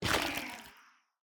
Minecraft Version Minecraft Version 1.21.5 Latest Release | Latest Snapshot 1.21.5 / assets / minecraft / sounds / block / sculk / break3.ogg Compare With Compare With Latest Release | Latest Snapshot
break3.ogg